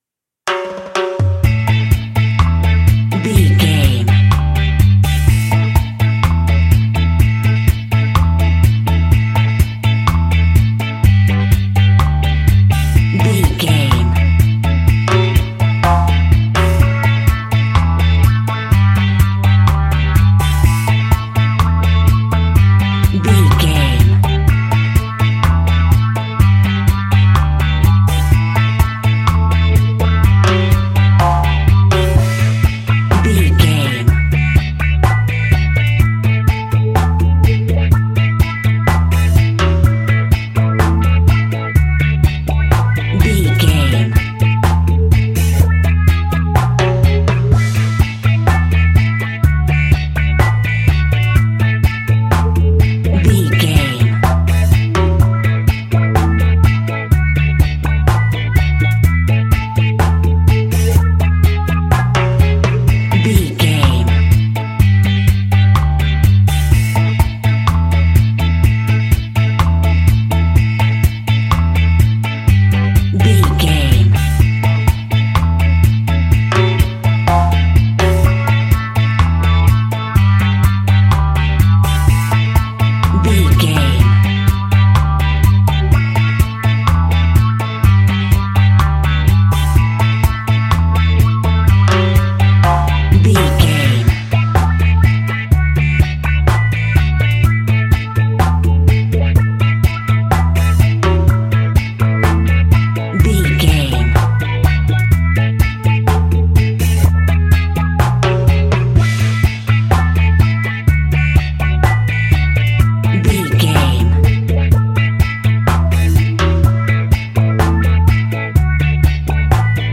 Classic reggae music with that skank bounce reggae feeling.
Ionian/Major
reggae instrumentals
laid back
chilled
off beat
drums
skank guitar
hammond organ
percussion
horns